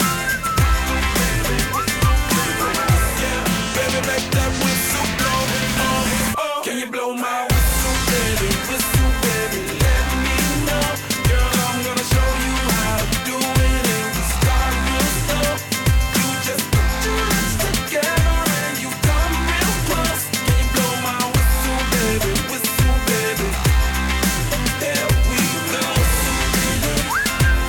WhistleLoop.ogg